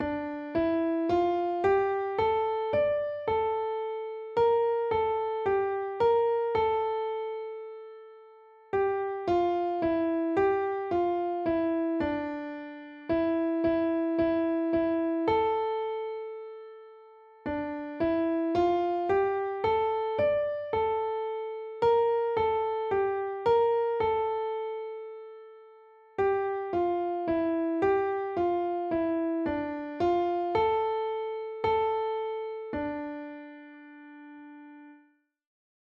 * It is terrific for practising a strong, heavy beat as children pretend to be dinosaurs.